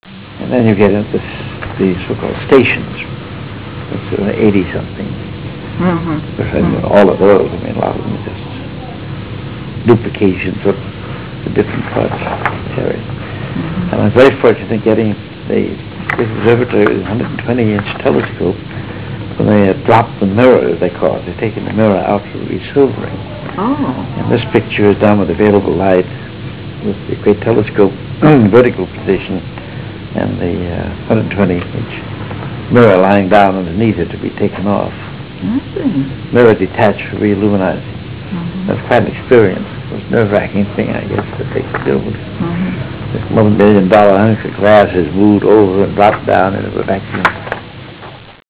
388Kb Ulaw Soundfile Hear Ansel Adams discuss this photo: [388Kb Ulaw Soundfile]